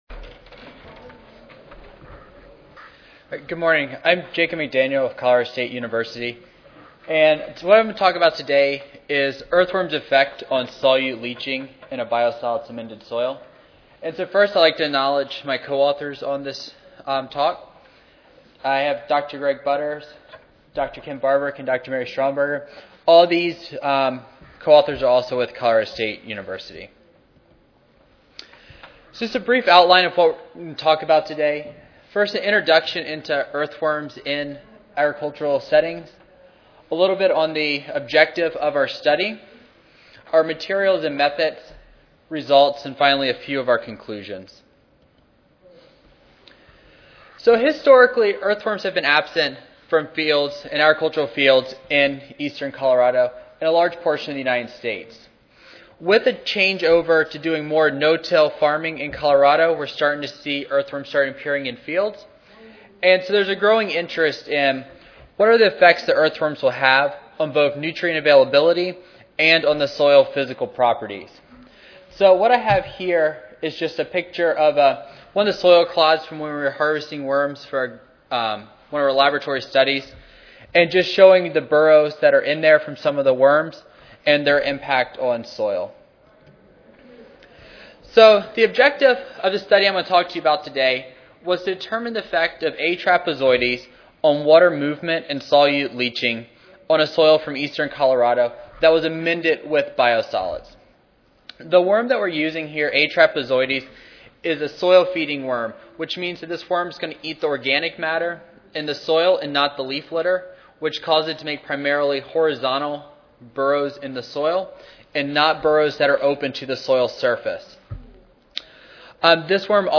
S11 Soils & Environmental Quality Session: Soil and Environmental Quality General Session: II (ASA, CSSA and SSSA Annual Meetings (San Antonio, TX - Oct. 16-19, 2011))
Colorado State University Recorded Presentation Audio File